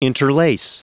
Prononciation du mot interlace en anglais (fichier audio)
Prononciation du mot : interlace